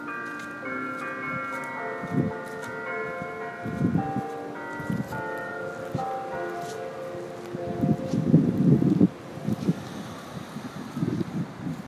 Just inland is the strange and rather twee Danish town of Solvang (or is it a village?).
Strangely the bells were playing an "English Country Garden"...not sure why.
solvable-bells-country-garden.m4a